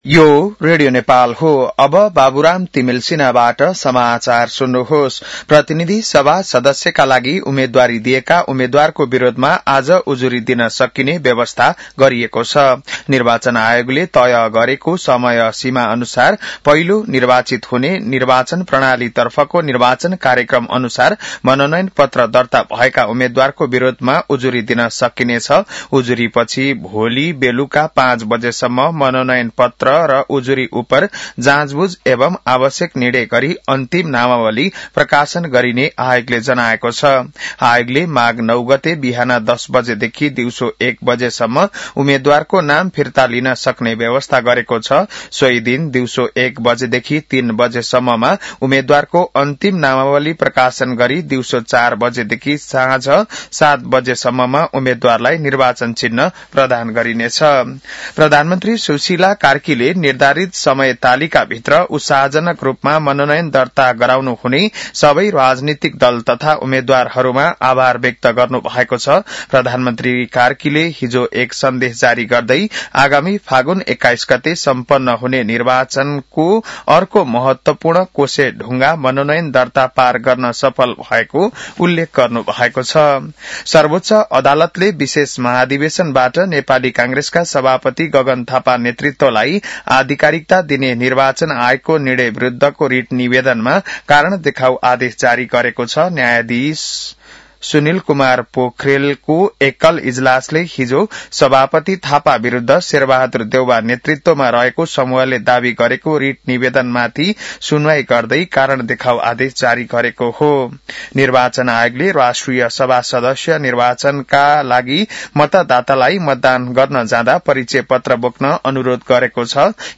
बिहान १० बजेको नेपाली समाचार : ७ माघ , २०८२